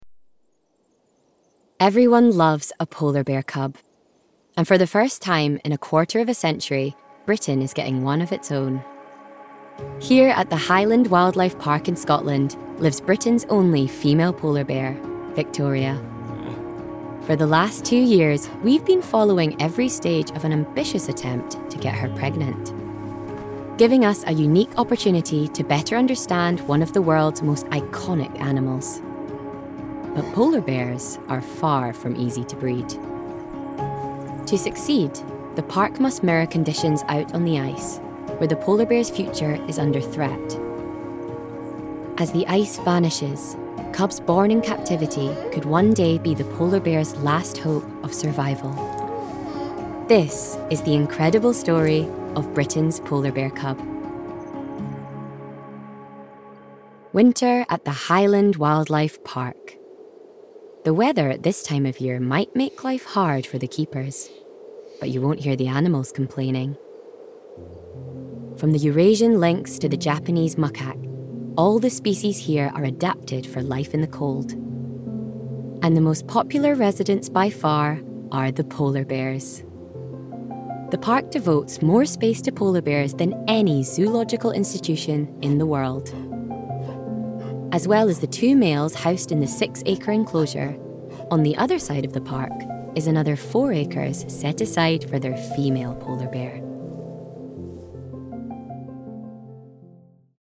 Voice Reel
TV Narration